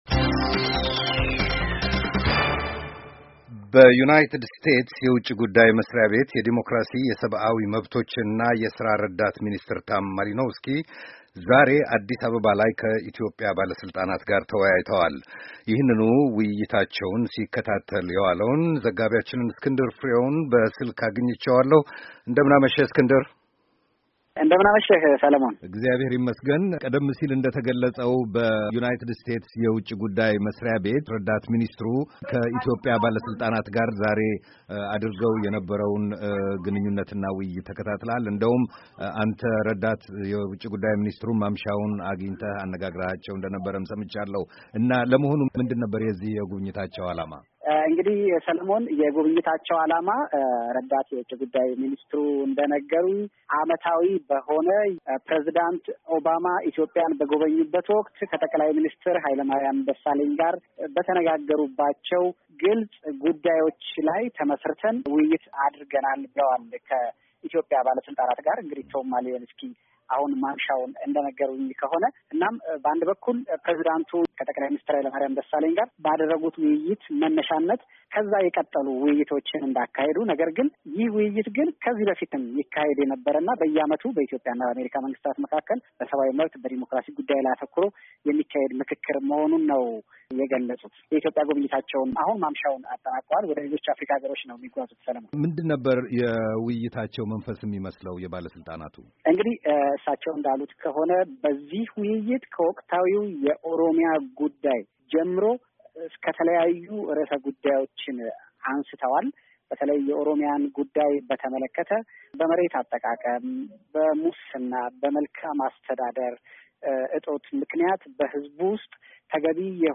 አዲስ አበባ —